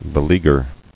[be·lea·guer]